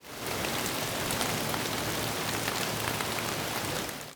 rain7.ogg